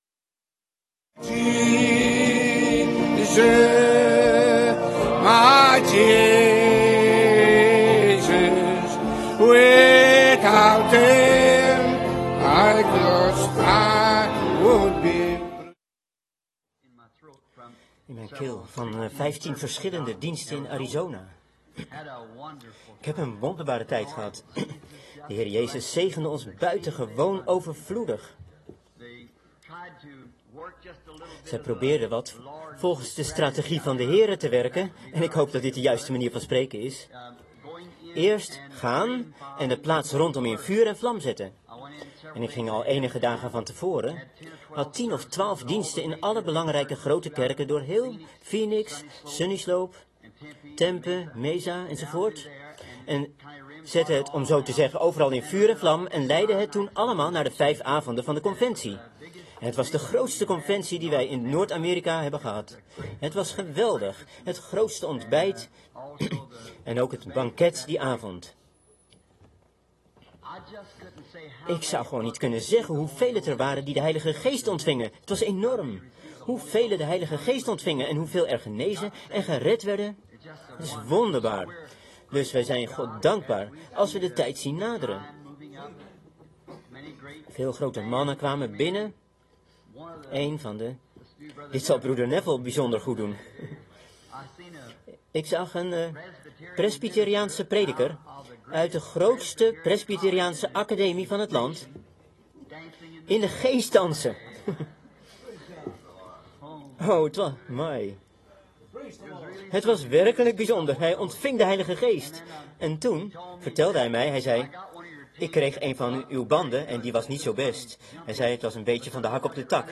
Vertaalde prediking